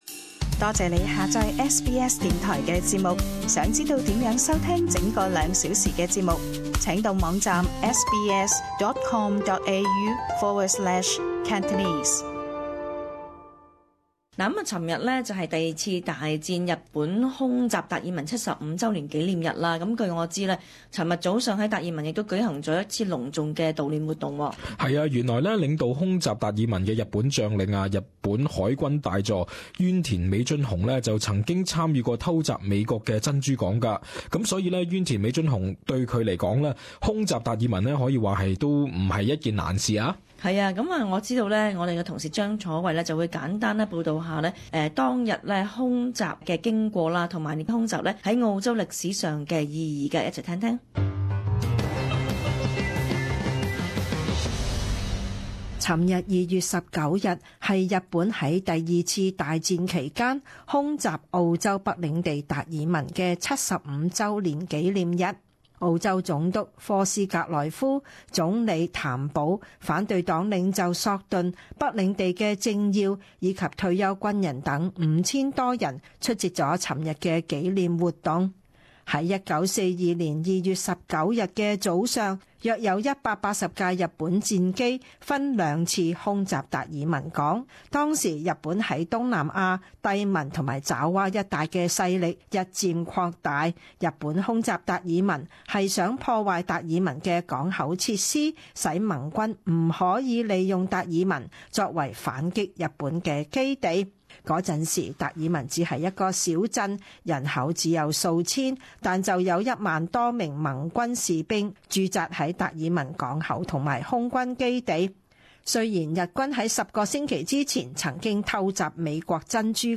時事報導：日本空襲達爾文75週年